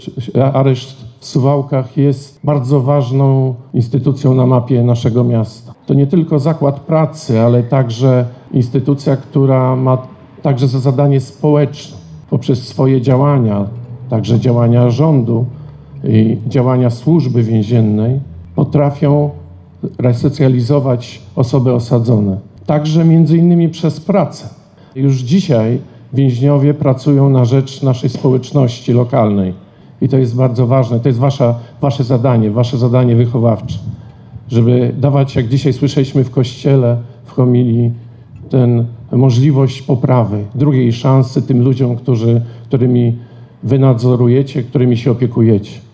– Suwalski Areszt Śledczy jest miejscem resocjalizacji. To bardzo ważne, aby więźniowie pracowali dla lokalnej społeczności – mówił Grzegorz Mackiewicz, przewodniczący Rady Powiatu Suwalskiego.